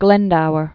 (glĕndouər, glĕn-dou-), Owen 1359?-1416?